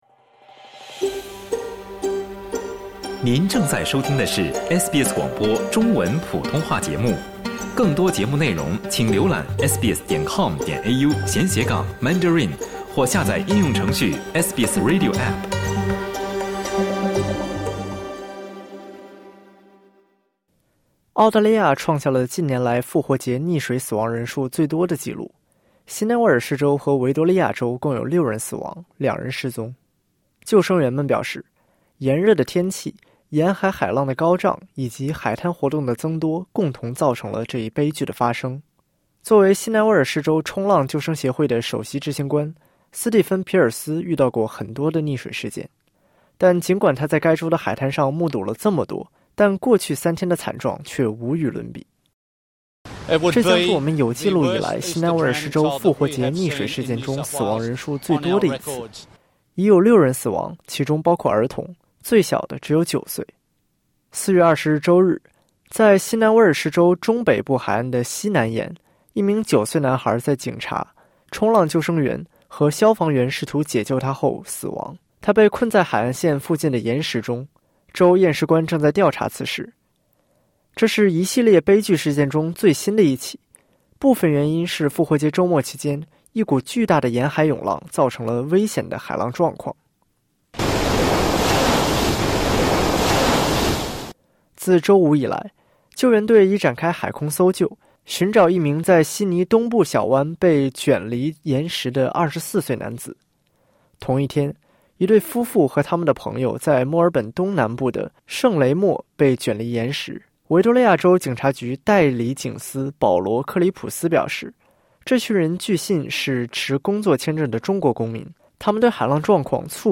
澳大利亚创下了近年来复活节溺水死亡人数最多的记录，新南威尔士州和维多利亚州共有六人死亡，两人失踪。救生员们表示，炎热天气、沿海海浪高涨以及海滩活动增多共同造成了这一悲剧的发生（点击音频，收听完整报道）。